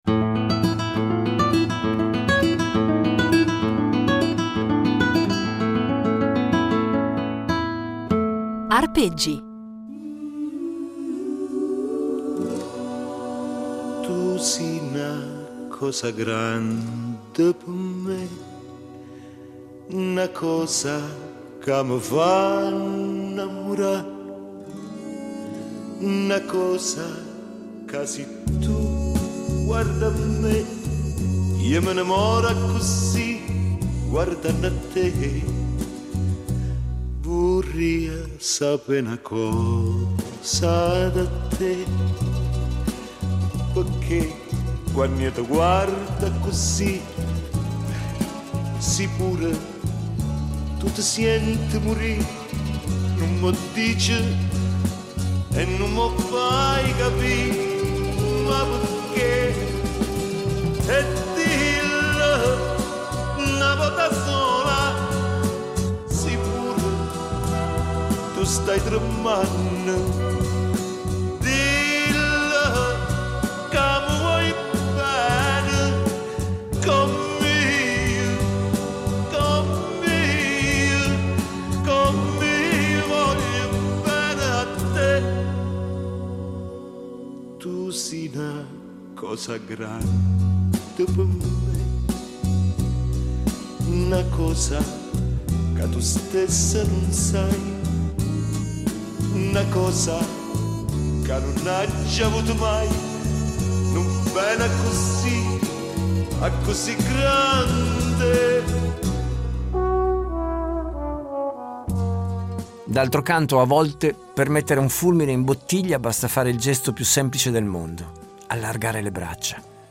sax
chitarra